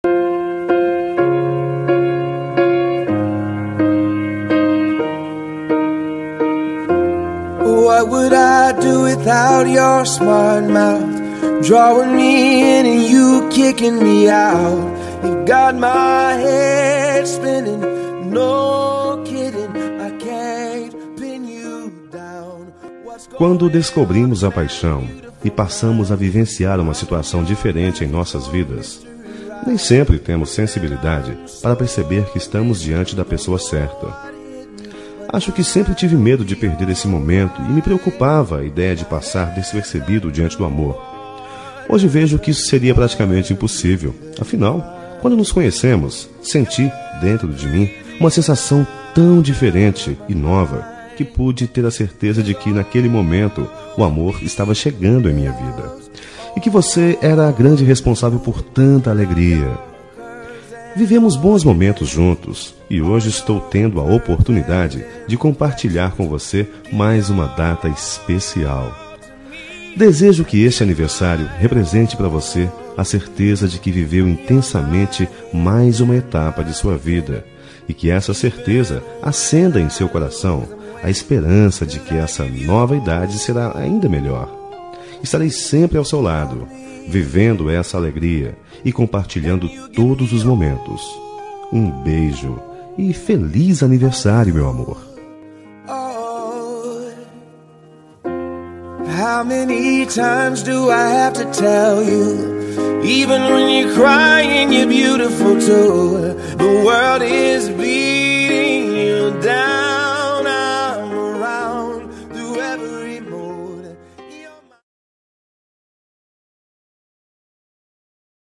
Aniversário Romântico – Voz Masculino – Cód: 350325